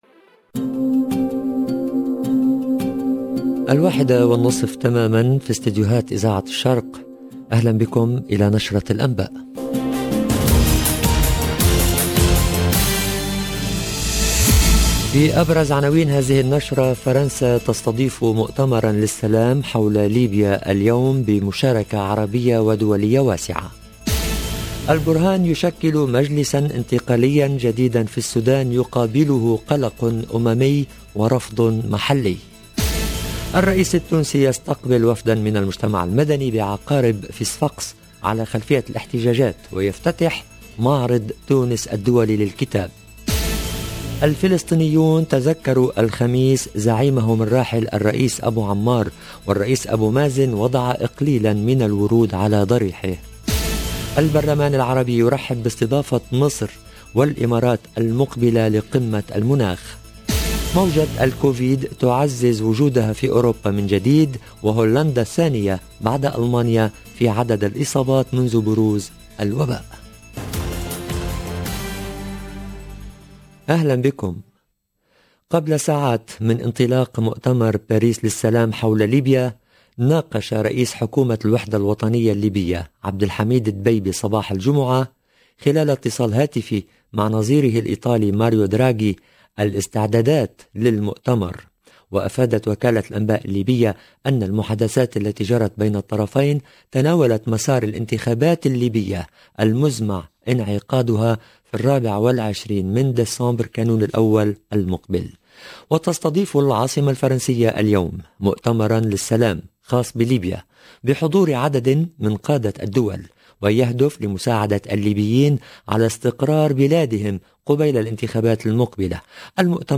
LE JOURNAL DE 13h30 EN LANGUE ARABE DU 12/11/21